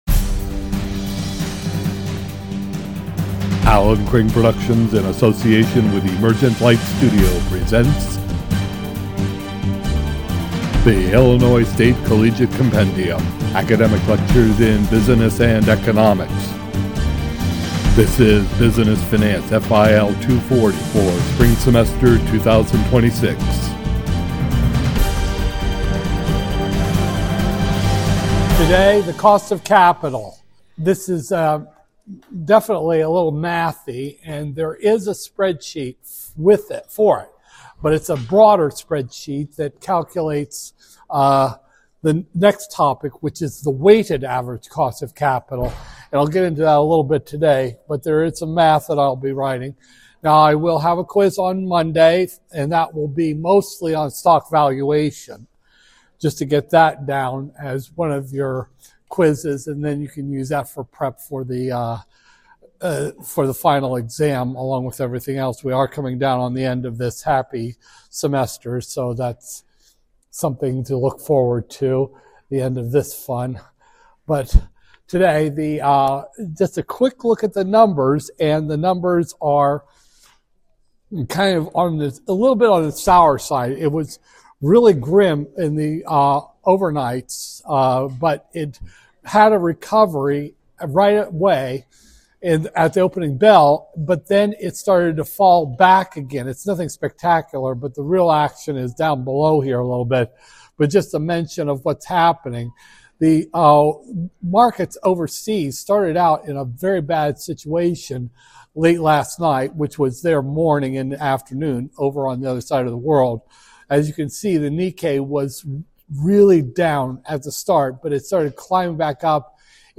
Business Finance, FIL 240-001, Spring 2026, Lecture 19